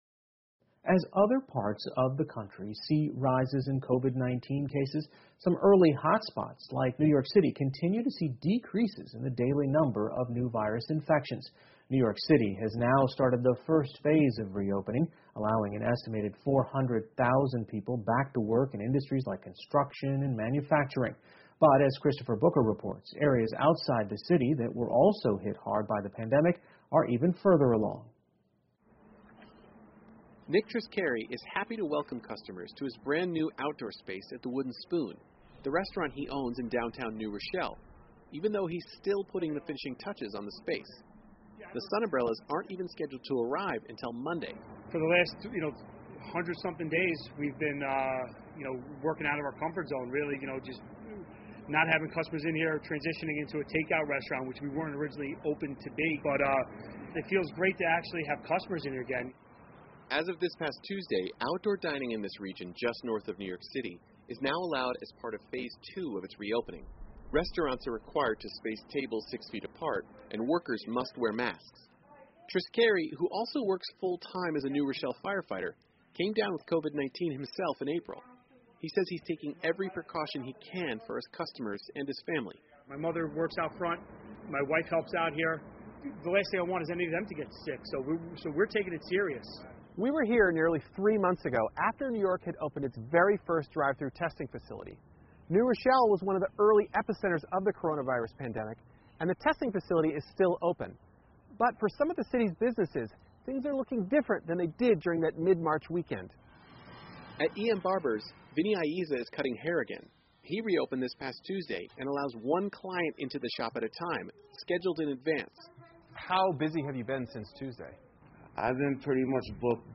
PBS高端访谈:新罗谢尔市重新开张 听力文件下载—在线英语听力室